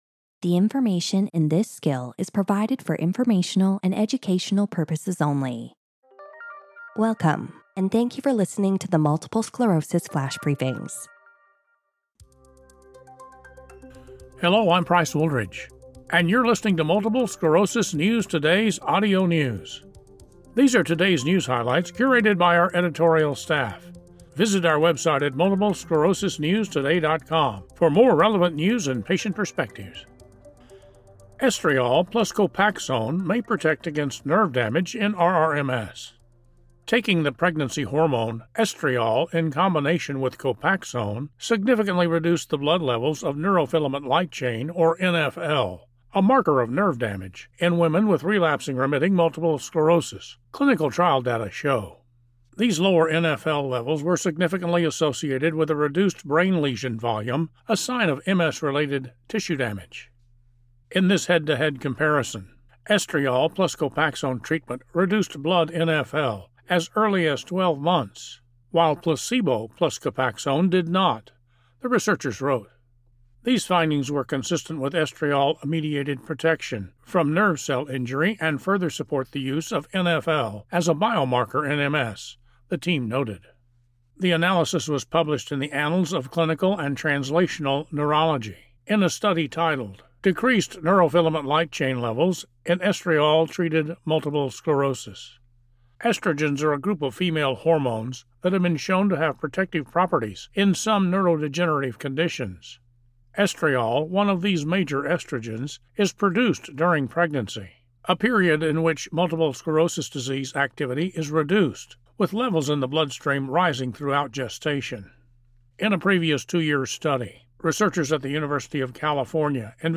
reads a news article about how the use of pregnancy hormone estriol with Copaxone significantly reduced levels of neurofilament light chain, a marker of nerve damage.